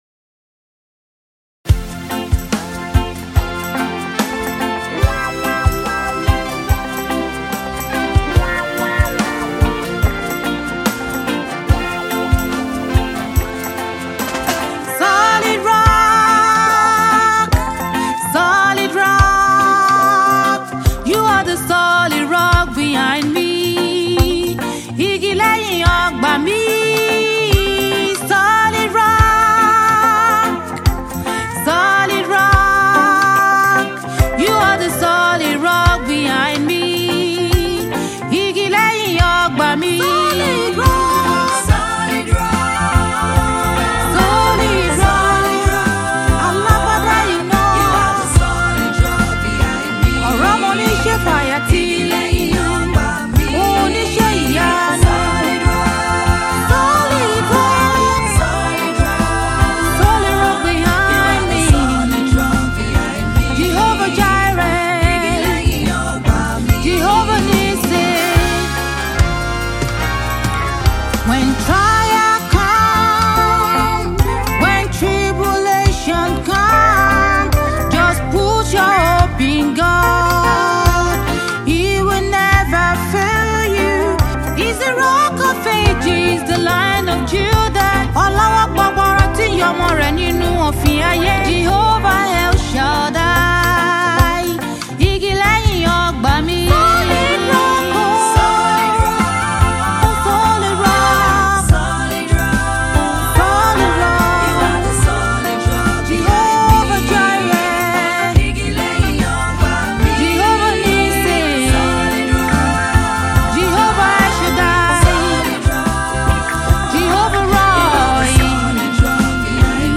Fast rising female gospel music minister
prolific saxophonist